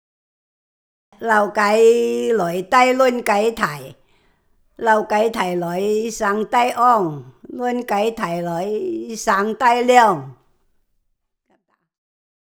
繼3年前〈傳統圍頭．客家歌謠與昔日鄉村生活誌〉的延續，將推出新的客家及圍頭傳統歌謠的光碟。